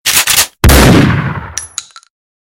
gun-message-tone_24636.mp3